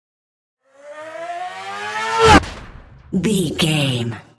Whoosh fast engine speed
Sound Effects
Fast
futuristic
intense
whoosh